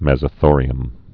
(mĕzə-thôrē-əm, mĕs-)